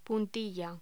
Locución: Puntilla
voz
Sonidos: Voz humana